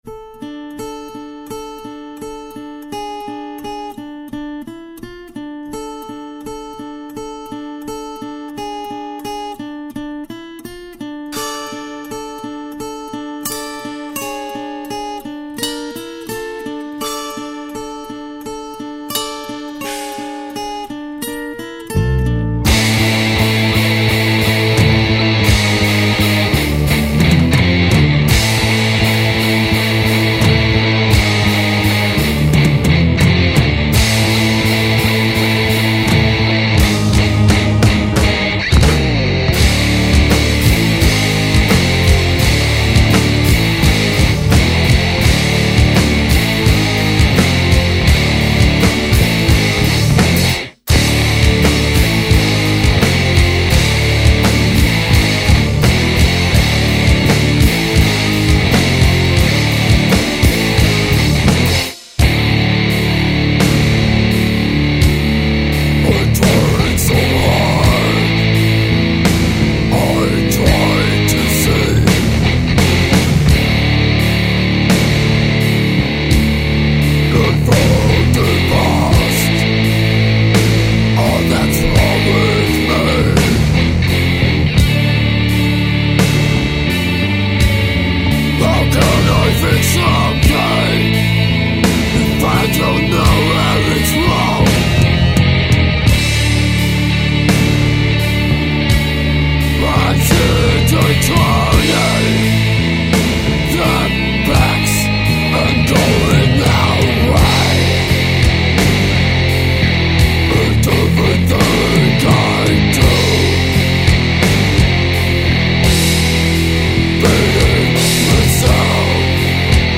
EstiloDeath Metal